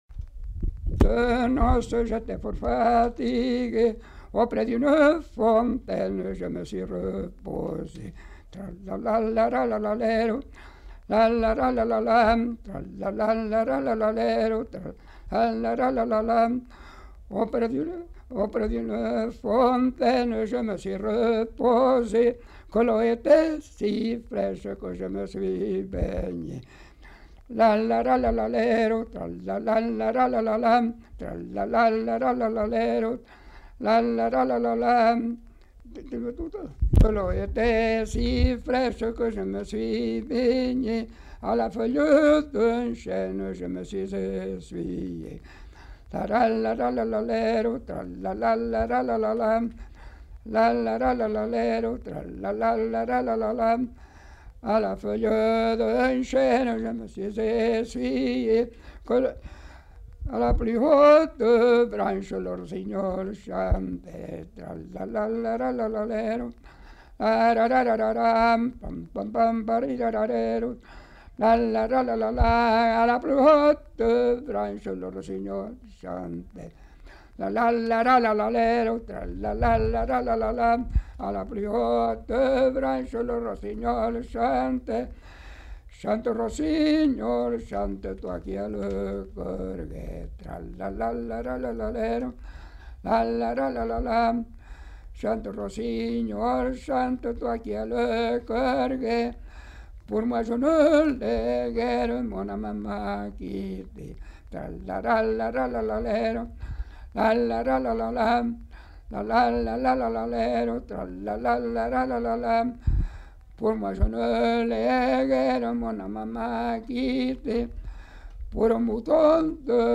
Aire culturelle : Savès
Lieu : Gers
Genre : chant
Effectif : 1
Type de voix : voix d'homme
Production du son : chanté